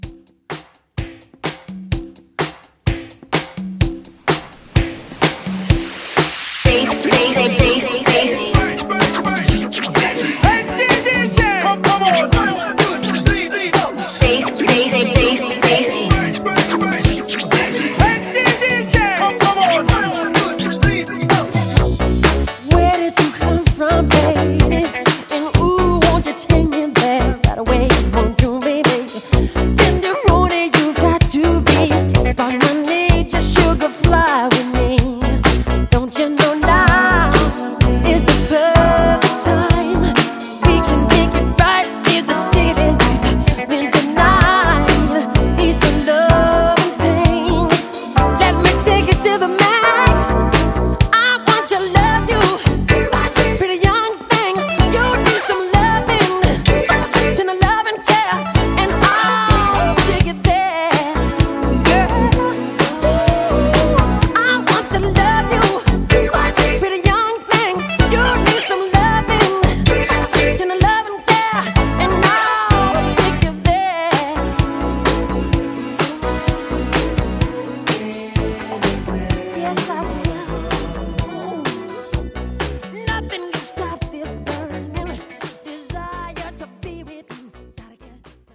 Genre: LATIN
Clean BPM: 100 Time